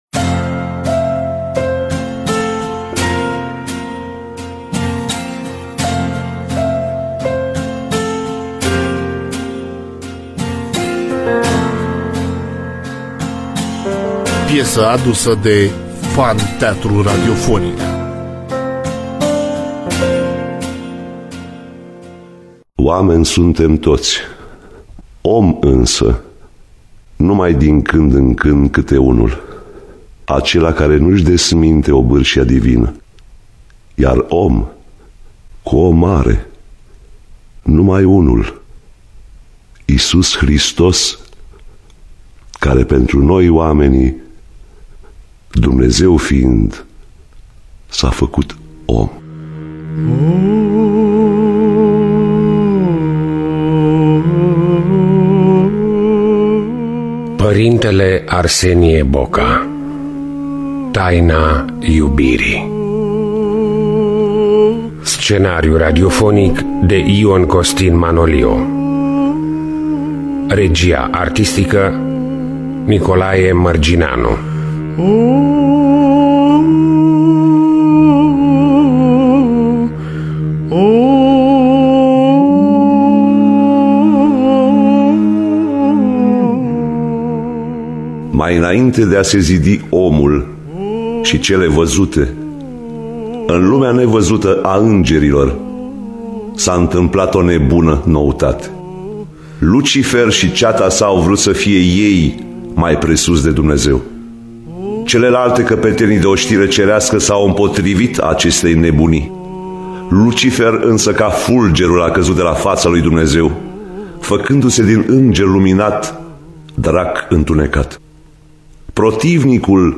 Scenariu radiofonic
Cântările bisericeşti